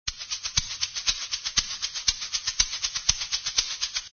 misc_shaker00.mp3